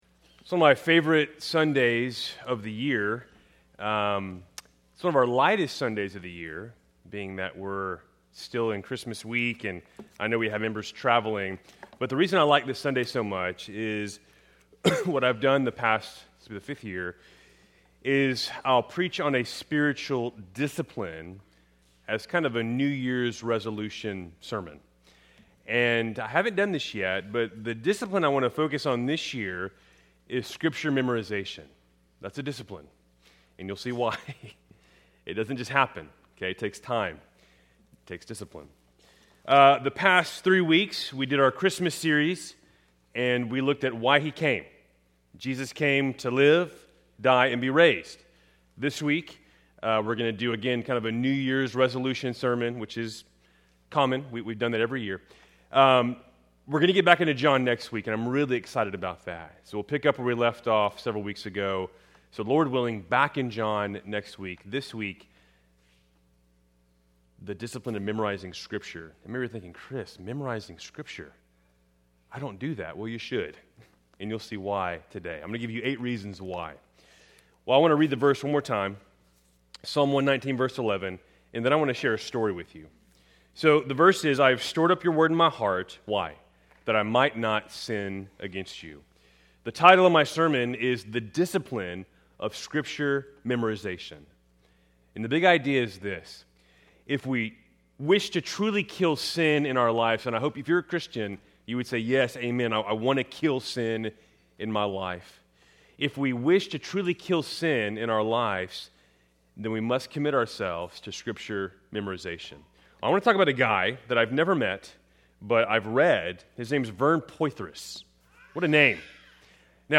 Keltys Worship Service, December 28, 2025